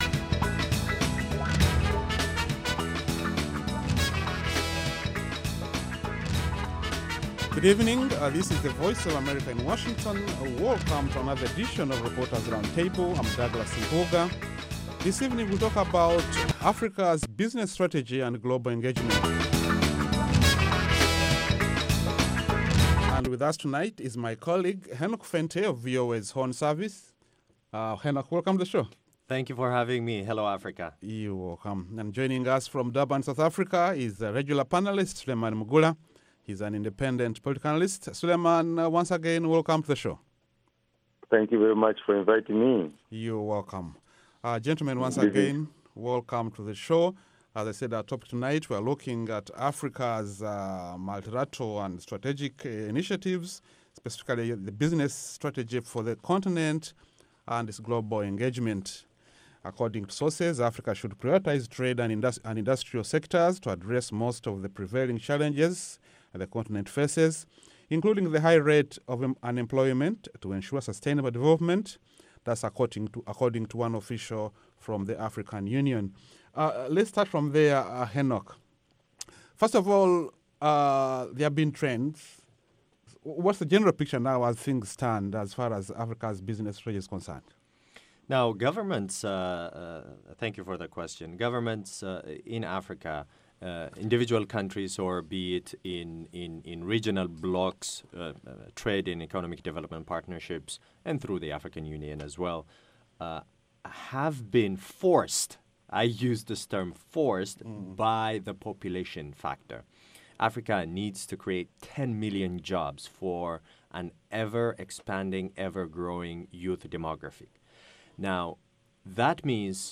along with a lively panel of journalists